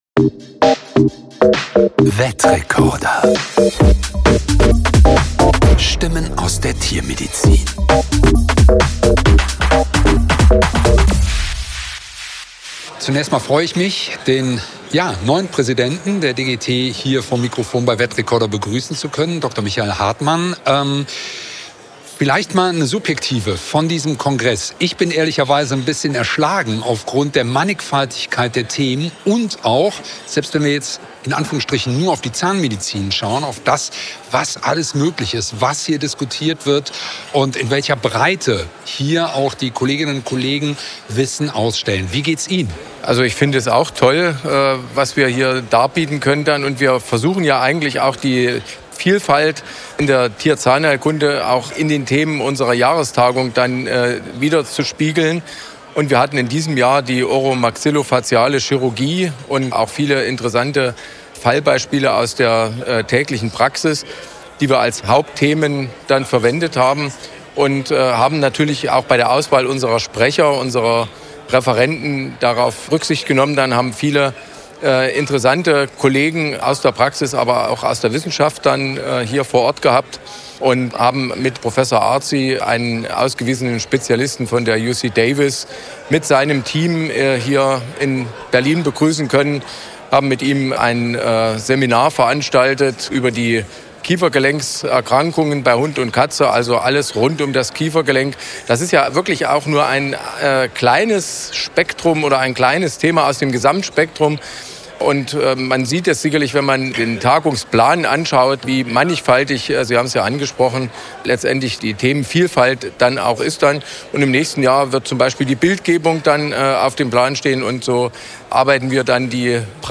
Live Interview vom DVG-VET Kongress - Sonderausgabe